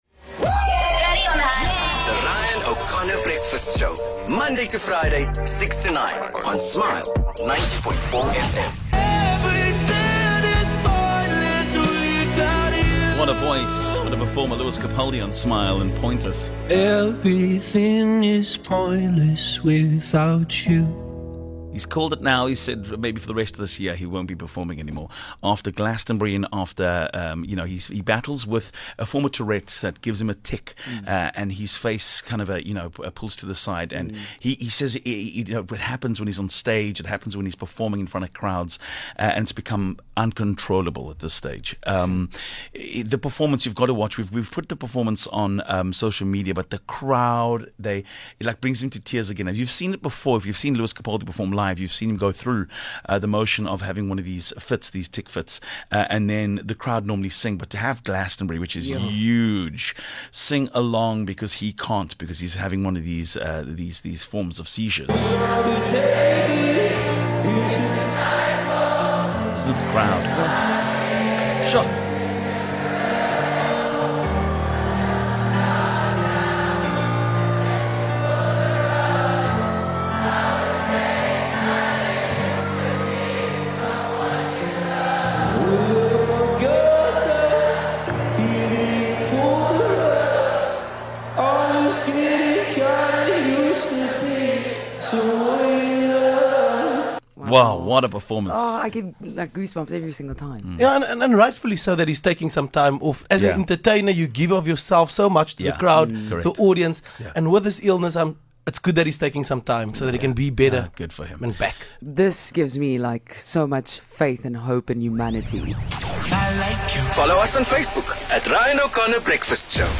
Lewis Capaldi has been open about his battle with Tourette syndrome and it has affected his live performances. Listen to the emotional moment he lost his voice during his performance at Glastonbury and the crowd helped him finish his set.